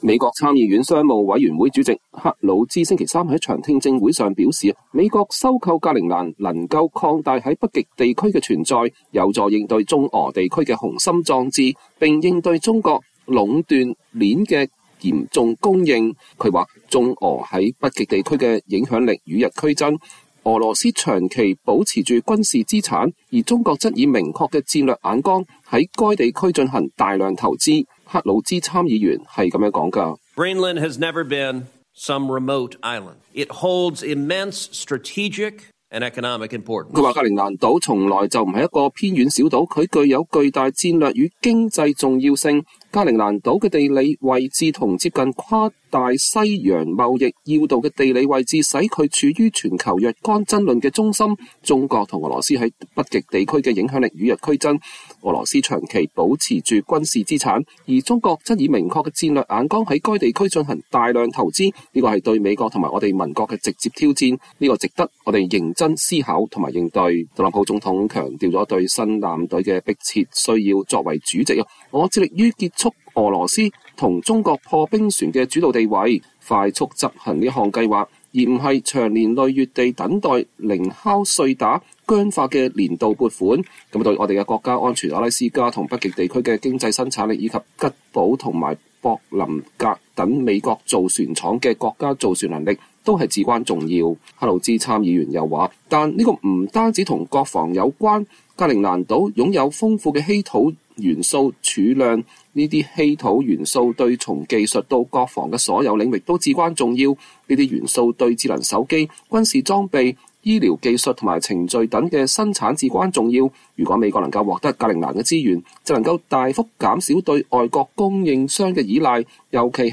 美國國會參議院商務、科學及運輸委員會主席泰德‧克魯茲(Ted Cruz)2月12日在該委員會聽證會上發言。